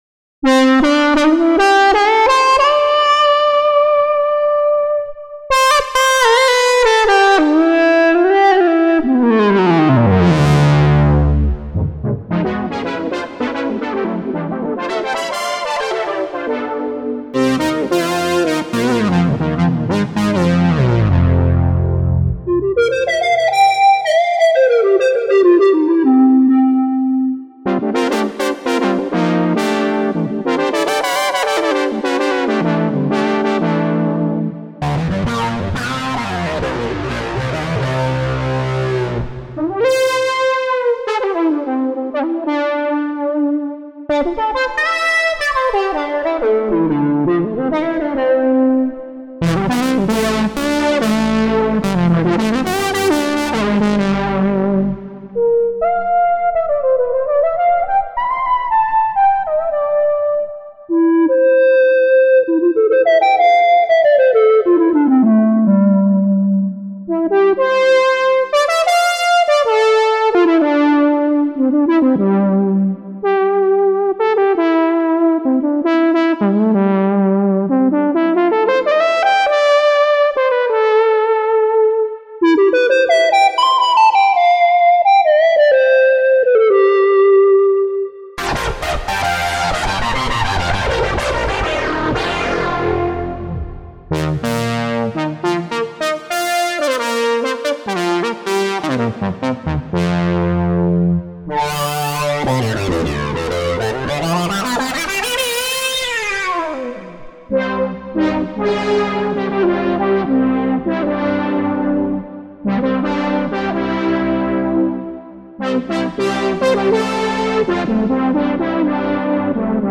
Most of the sounds in this soundbank are recorded in this extended demo starting from the top of the list to the end. This demo was recorded directly into Logic from just one instance of the ES2 softsynth with a little reverb added to fill in the sound.